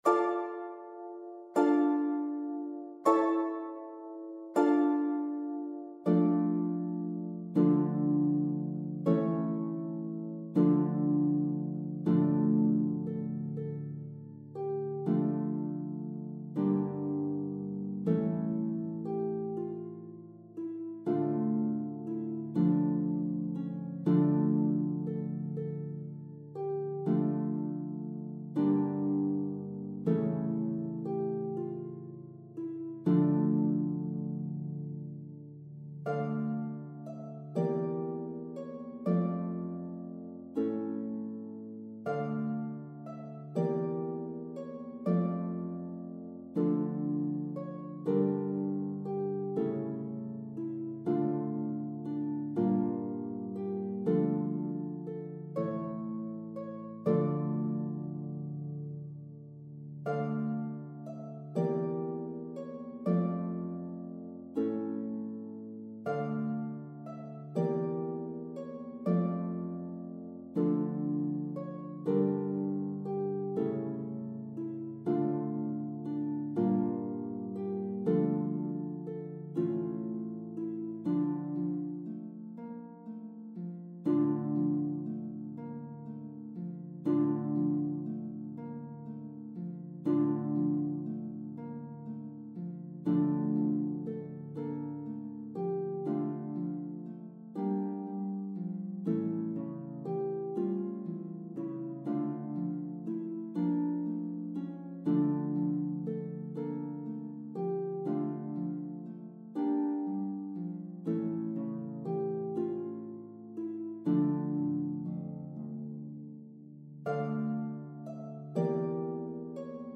This Renaissance sounding melody was composed by Frenchman